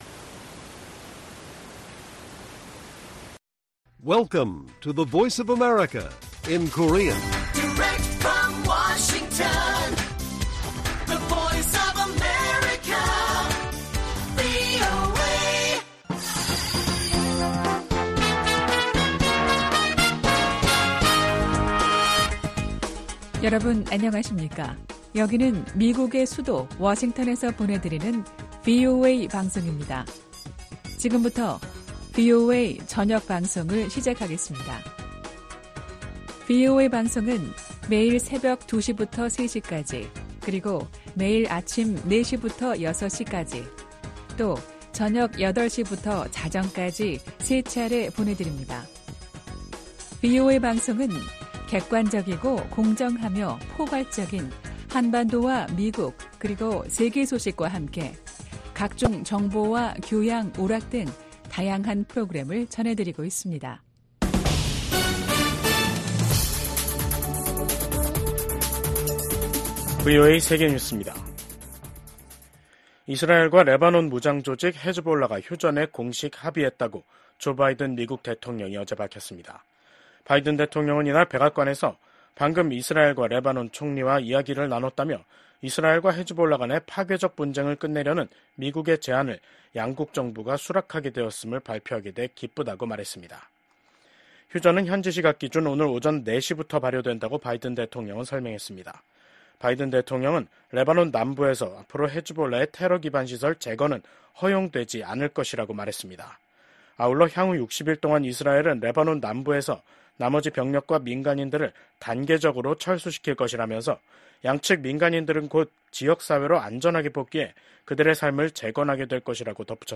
VOA 한국어 간판 뉴스 프로그램 '뉴스 투데이', 2024년 11월 27일 1부 방송입니다. 미국 국무장관은 미국 등 주요 7개국이 북한이 병력 파병 대가로 러시아로부터 핵과 미사일 기술을 넘겨받을 가능성을 주시하고 있다면서, 중국이 대북 영향력을 발휘하지 않으면 미국은 한반도에서 억지력 강화를 위한 추가 조치를 취할 것이라고 경고했습니다.